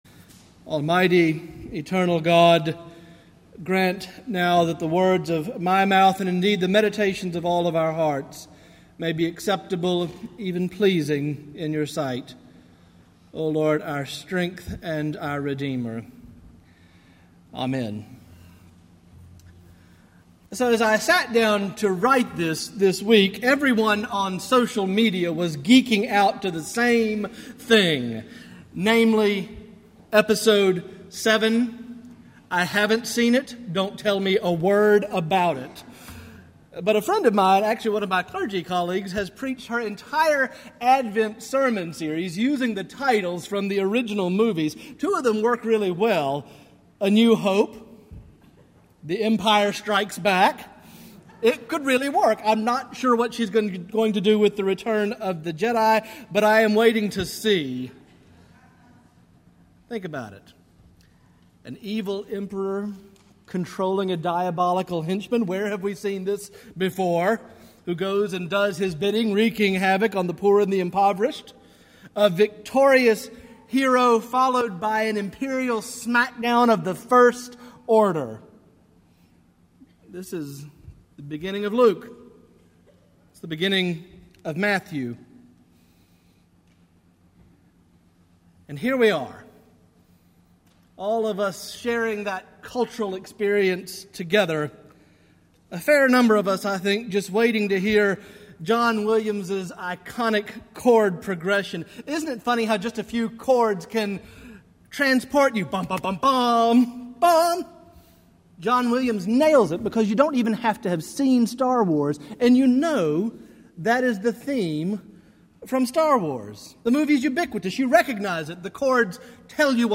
Morningside Presbyterian Church - Atlanta, GA: Sermons: An Old Familiar Song